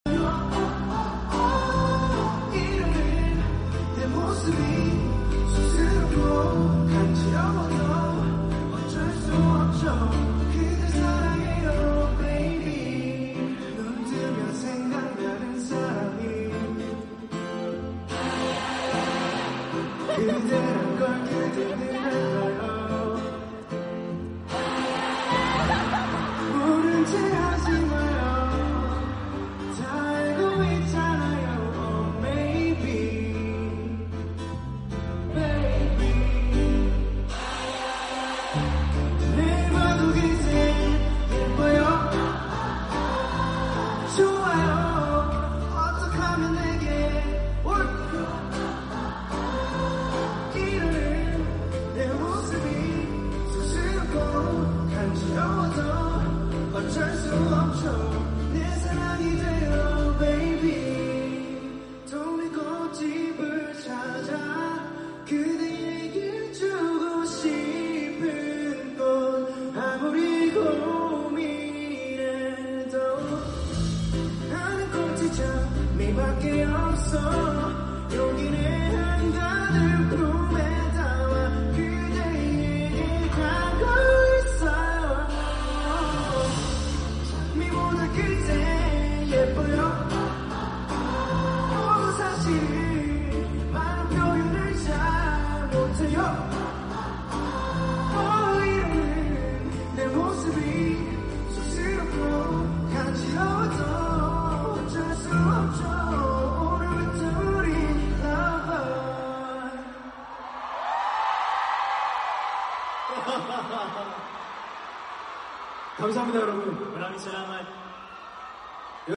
I’m really looking forward to hearing it live!